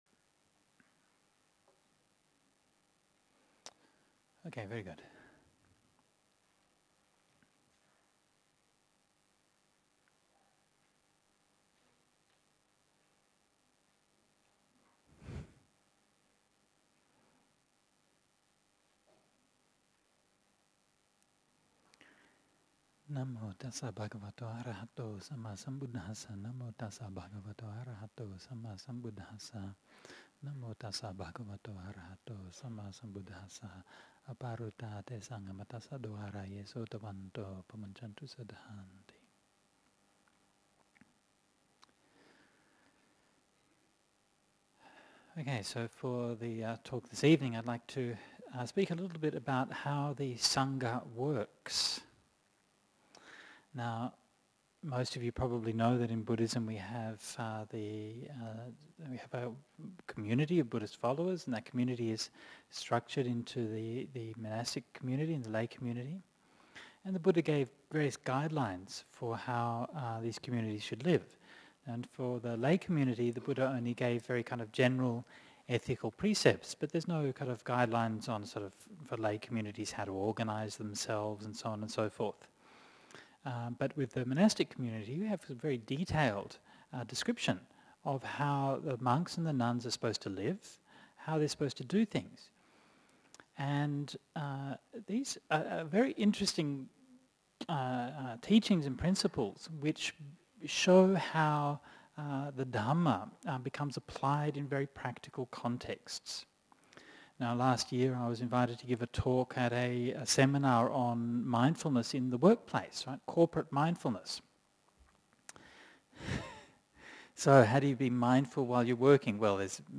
This is just one example of a talk on Vinaya matters: